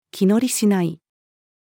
気乗りしない-female.mp3